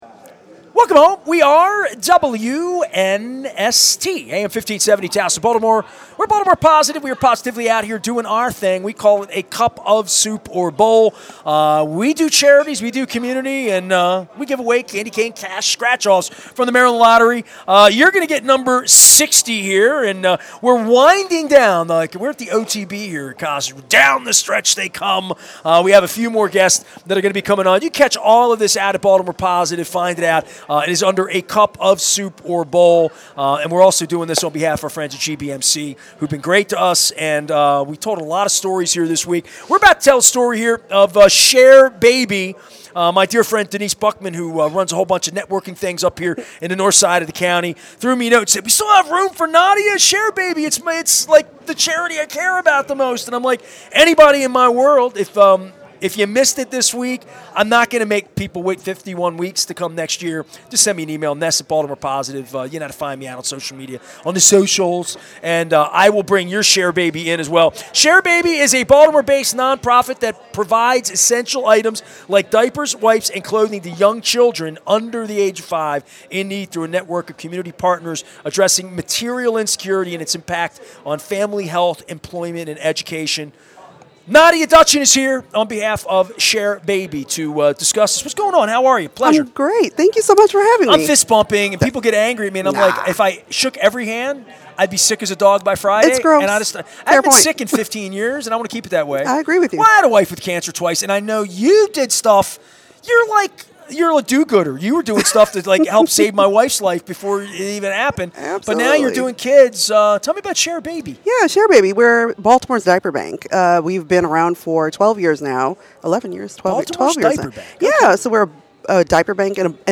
at Costas Inn in Timonium